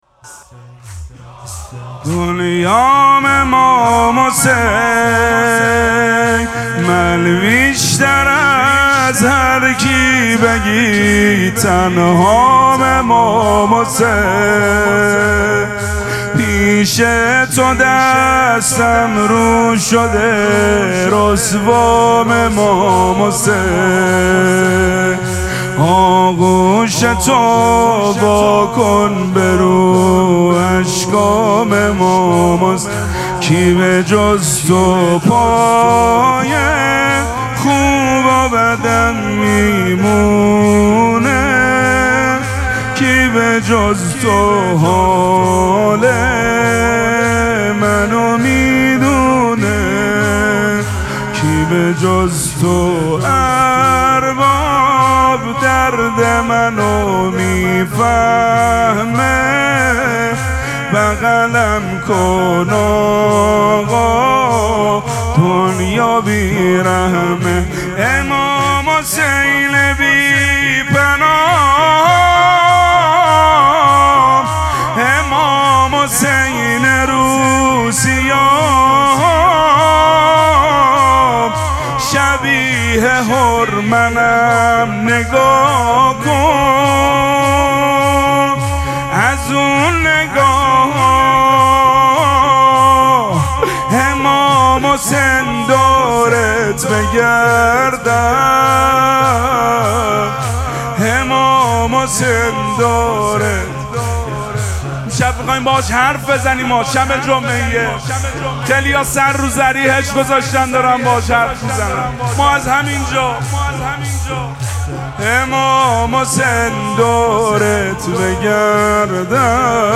مراسم مناجات شب سیزدهم ماه مبارک رمضان
حسینیه ریحانه الحسین سلام الله علیها